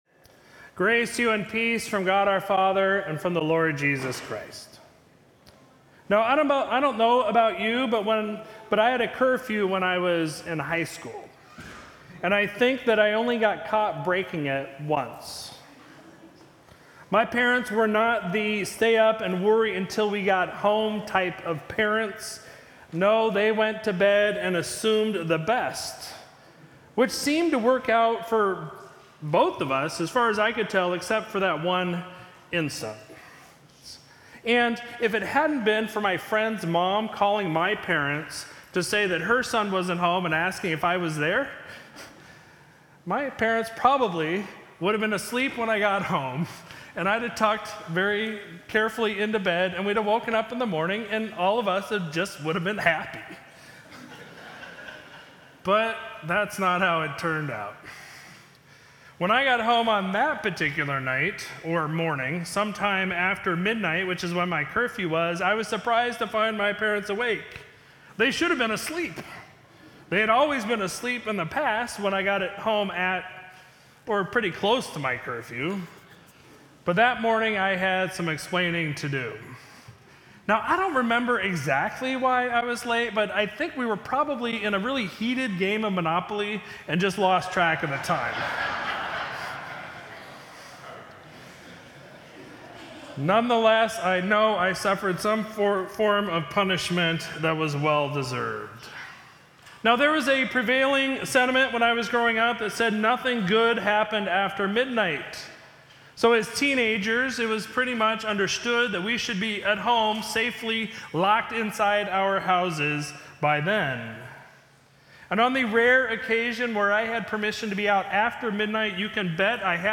Sermon for Sunday, August 28, 2022